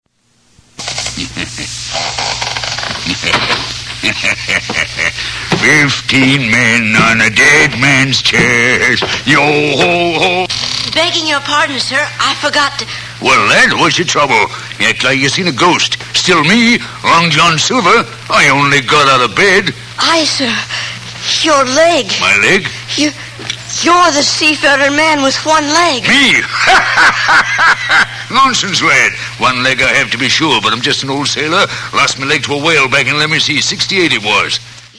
Basil Rathbone narrates Treasure Island and Robin Hood -- one 33.3 rpm record, P13895.